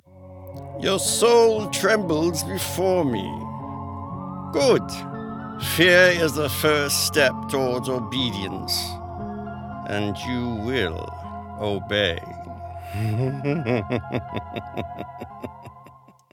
Video Game Voiceover
Video Game Demos